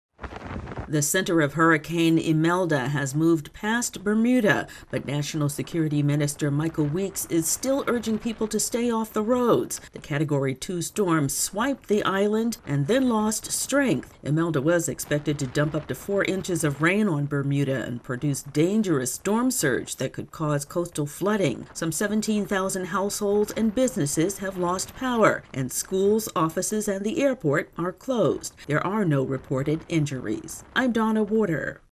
Bermuda officials are asking residents to stay off the roads after a Category 2 hurricane hit the island. AP correspondent